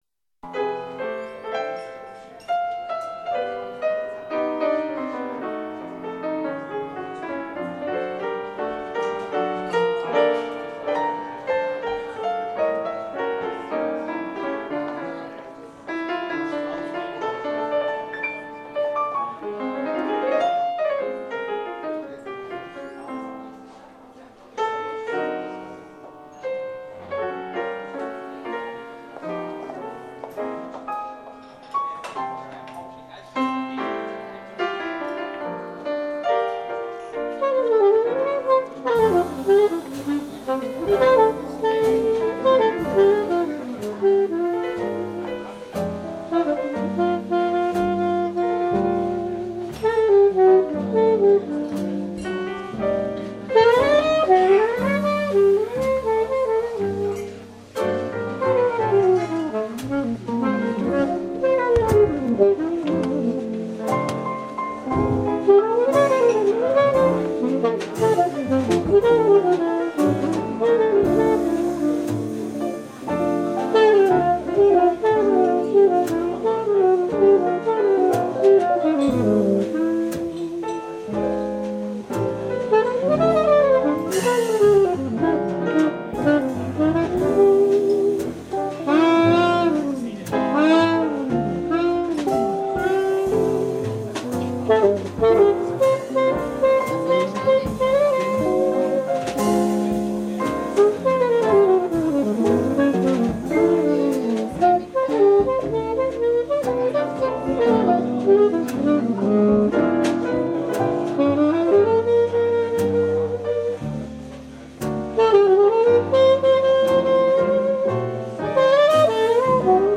alto sax
chitarra
piano
contrabbasso Ascolta Demo Listen or download